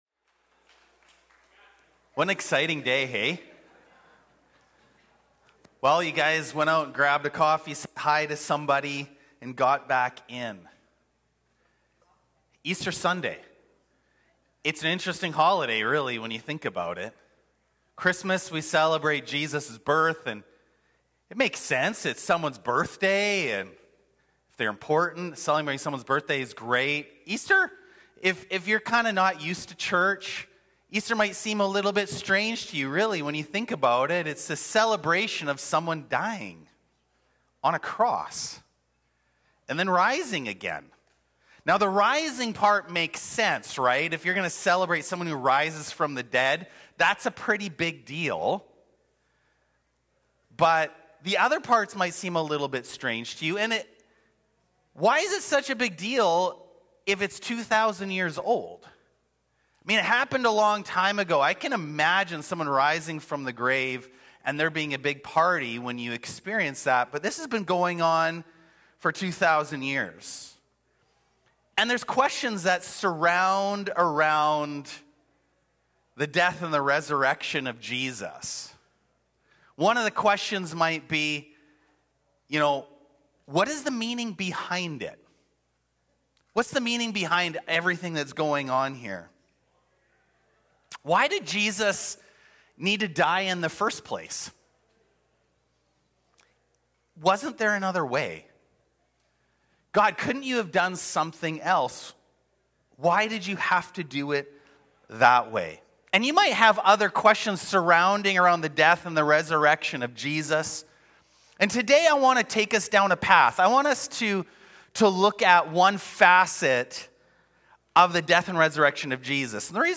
Sermons | Gateway City Church